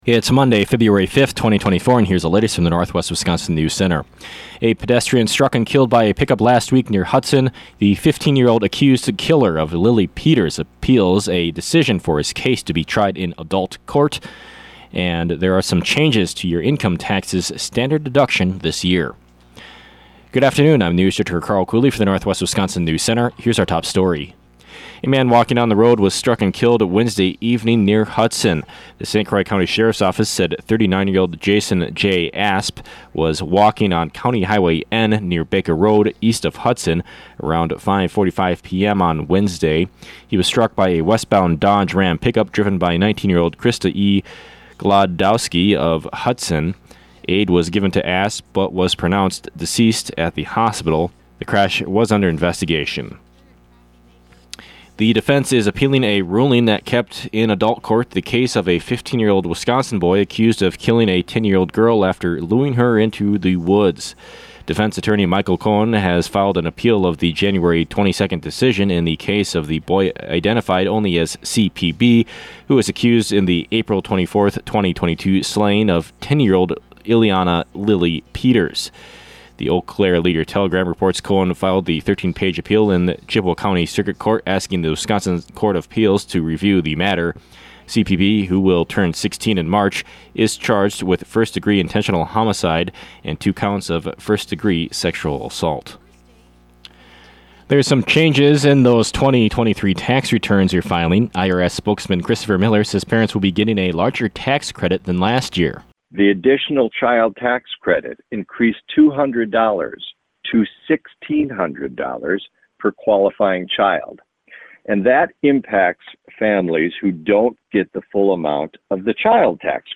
These stories and more on today’s local newscast.